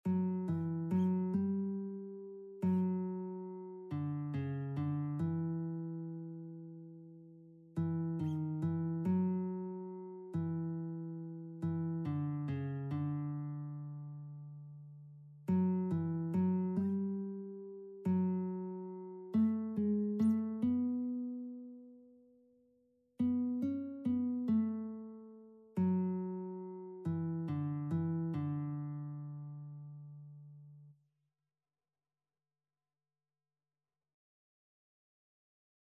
Christian
9/4 (View more 9/4 Music)
Classical (View more Classical Lead Sheets Music)